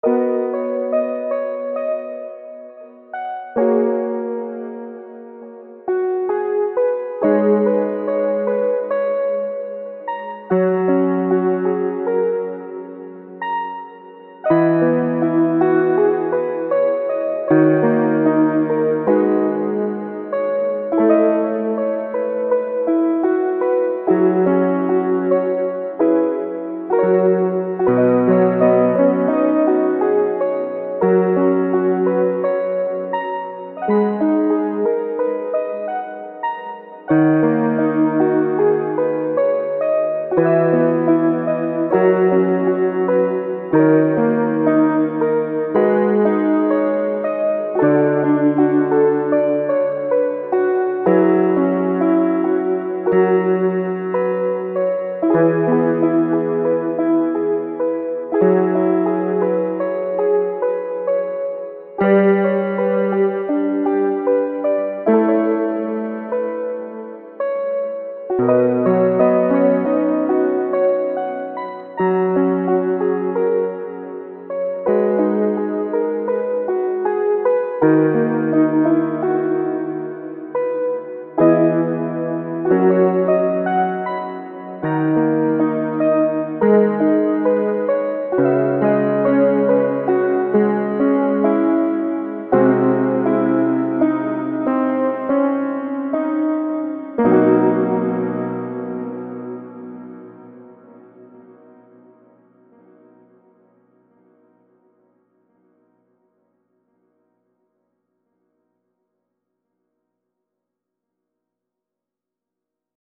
未分類 バラード ピアノ 懐かしい 穏やか 青春 音楽日記 よかったらシェアしてね！